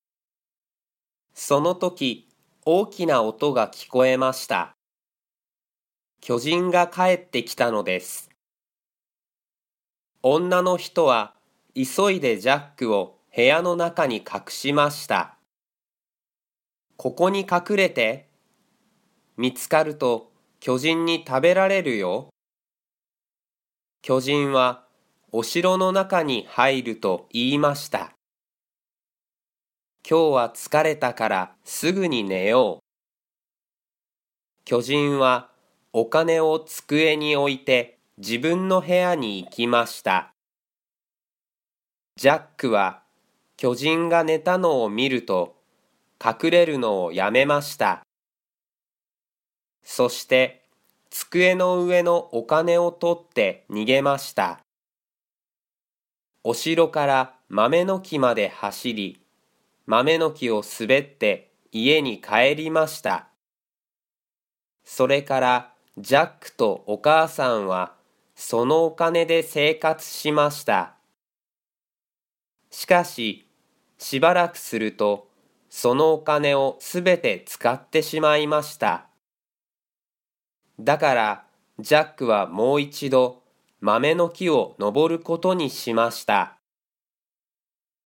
Japanese Graded Readers: Fairy Tales and Short Stories with Read-aloud Method
Natural Speed